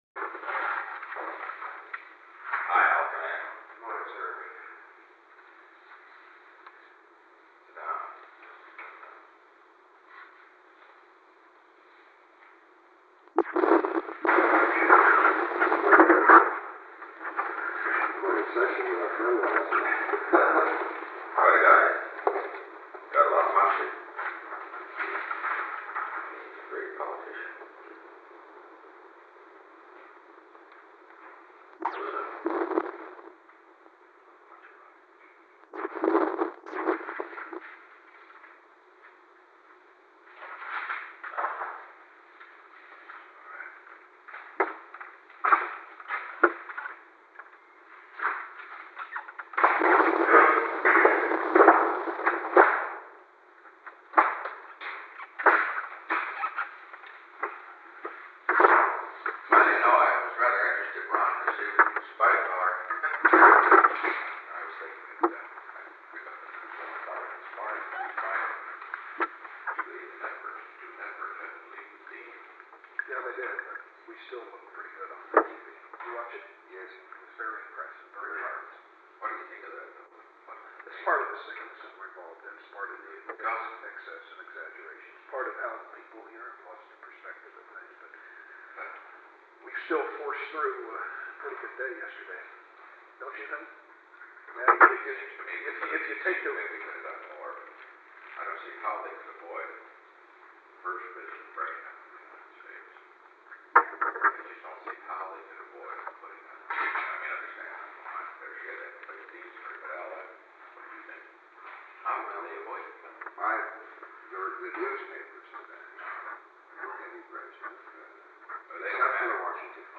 Conversation No. 945-3
Location: Oval Office
Secret White House Tapes | Richard M. Nixon Presidency